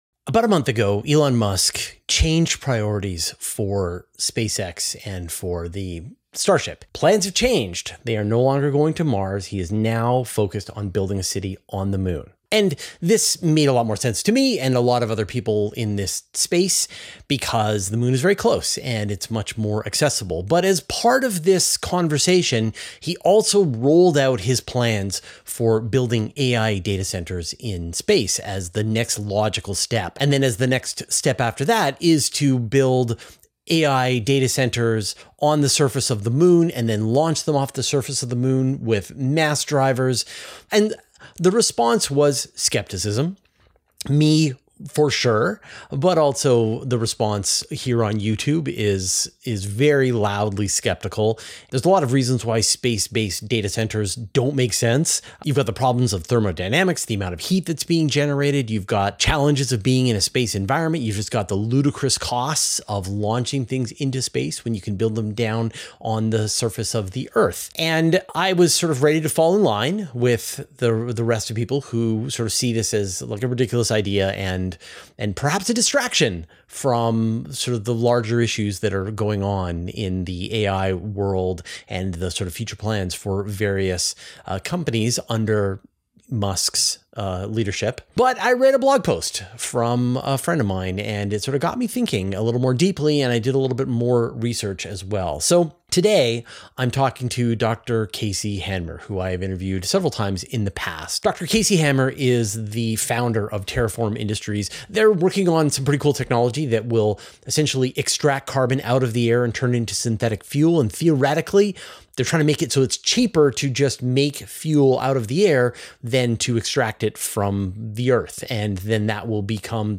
[Interview+] SpaceX's AI Data Centres Might Actually Be A Good Idea.